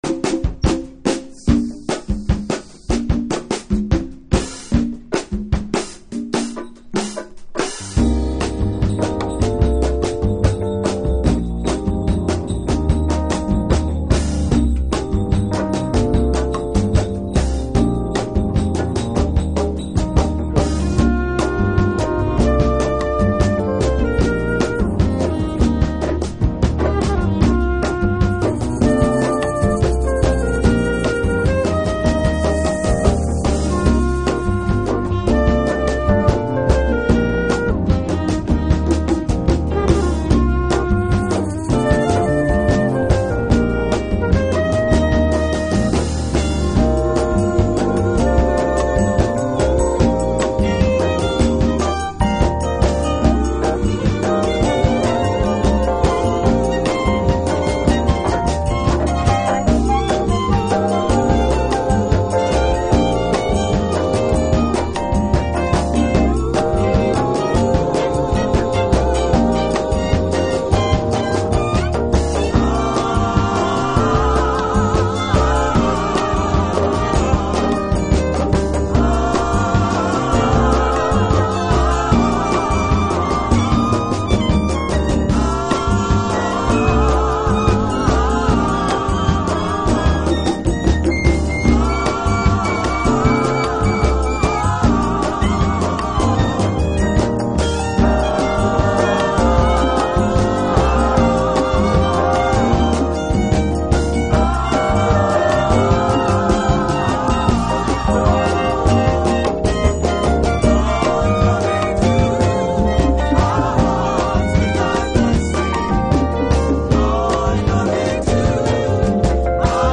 Jazz / Crossover
名曲のリメイクを含めながら70年代ブラックジャズの精神性も引き継いだサウンド。